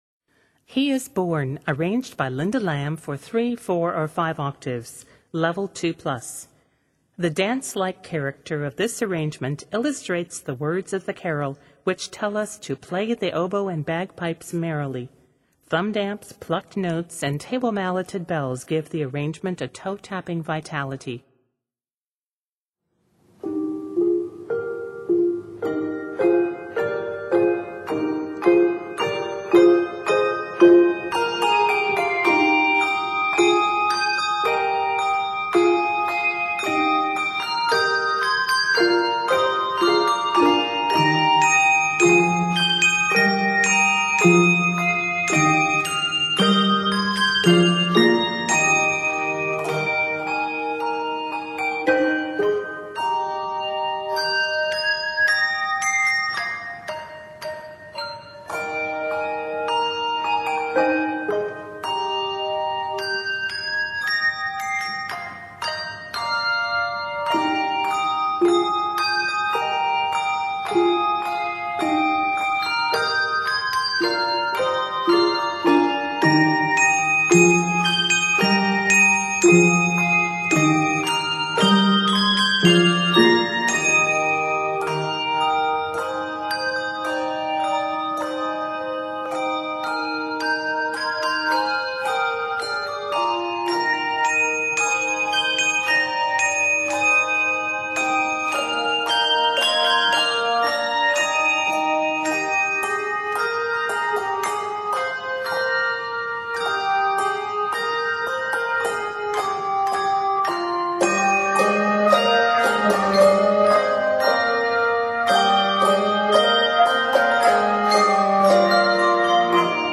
lively arrangement